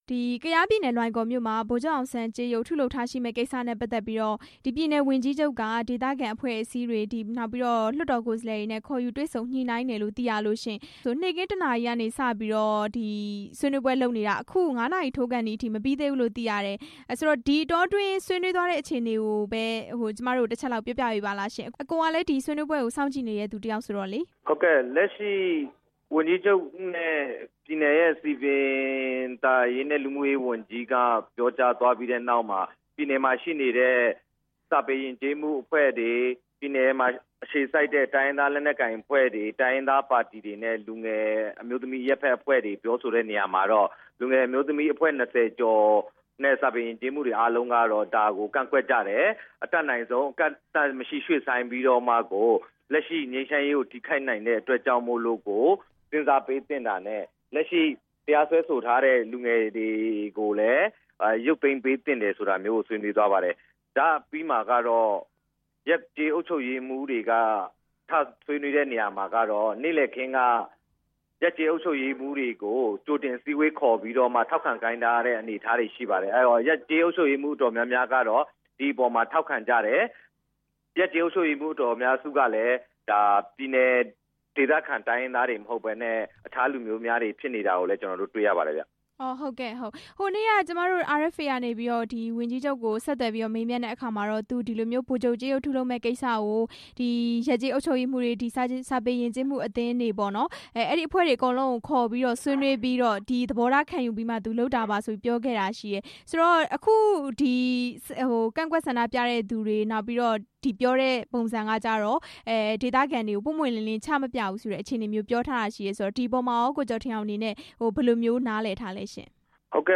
ဗိုလ်ချုပ်ကြေးရုပ်ထားရှိမယ့်ကိစ္စအကြောင်း မေးမြန်းချက်